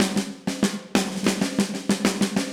AM_MiliSnareC_95-03.wav